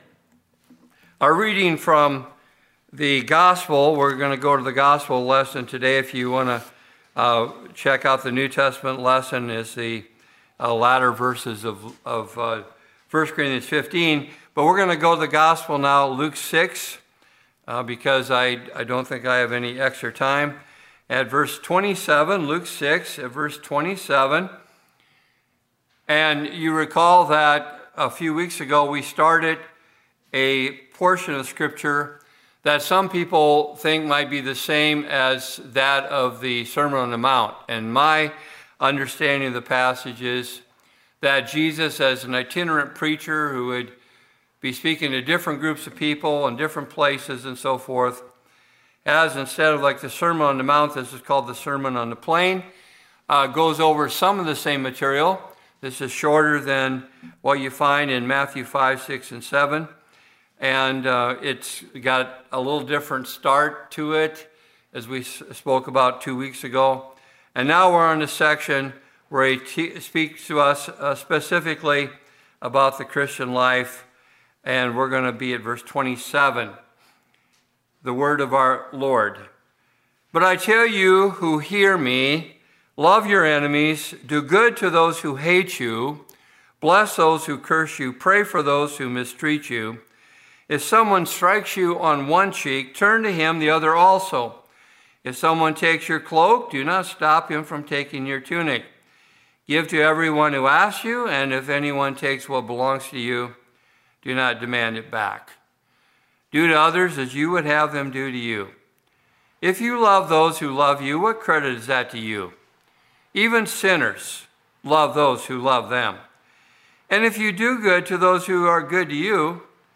In this sermon, our Lord lists the ways in which we are ordered to show love to our enemies.